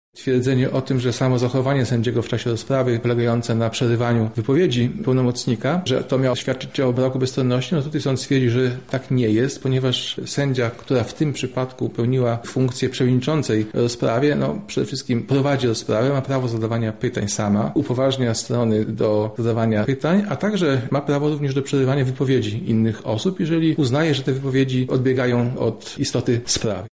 Wniosek został oddalony, a decyzje sądu tłumaczy sędzia Grzegorz Wołejko z Wojewódzkiego Sądu Administracyjnego: